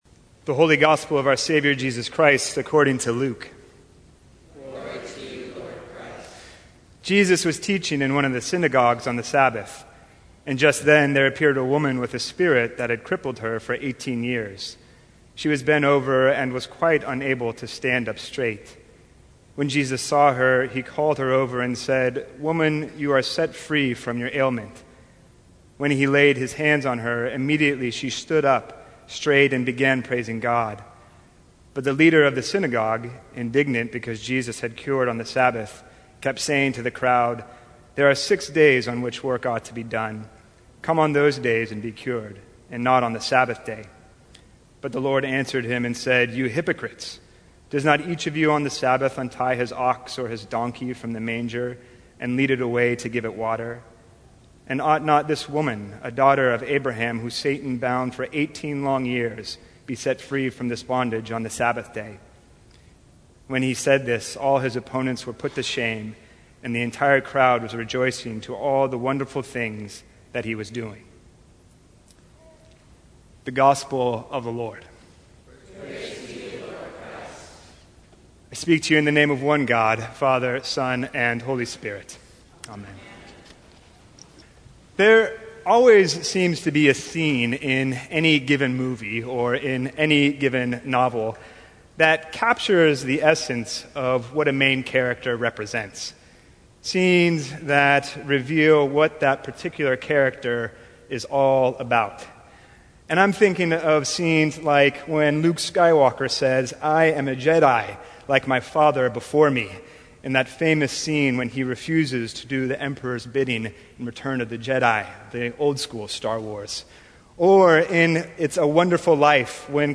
Sermons from St. Cross Episcopal Church What’s Holding Us Back?